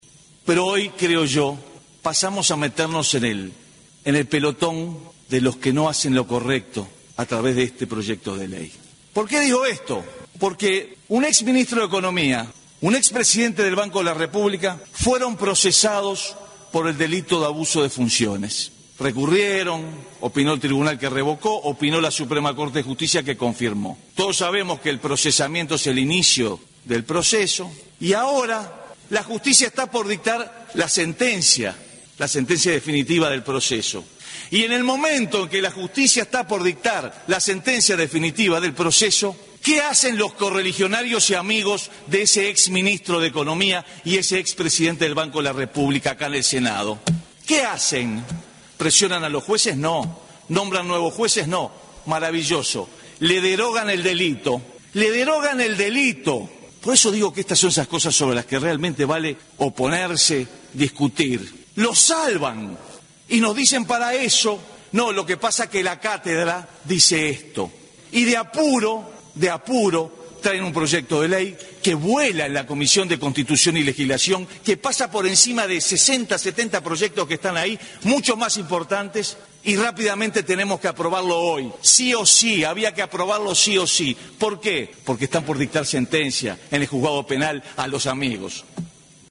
Durante el debate, el senador del Partido Colorado, Pedro Bordaberry, anunció que si el delito es derogado comenzará una campaña de recolección de firmas para llamar a un referéndum en el que la población deberá decidir «si quiere combatir la corrupción o derogar el delito».